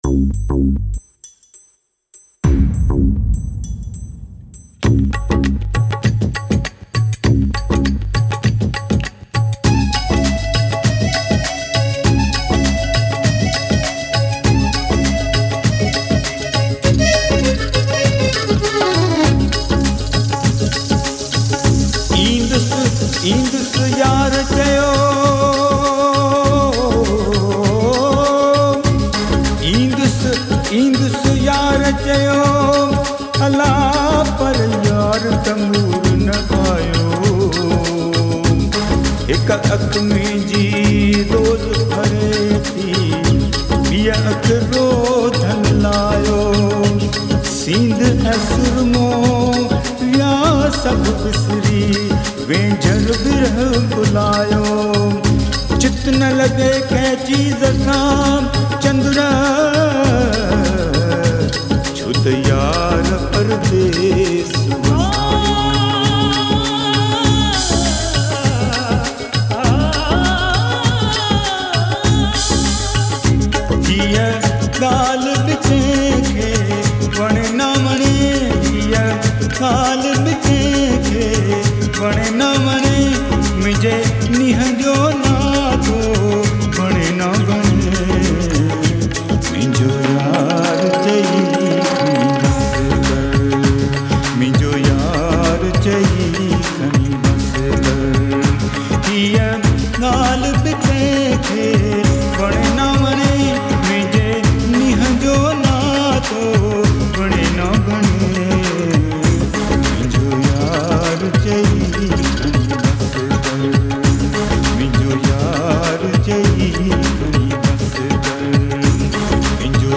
Sindhi Music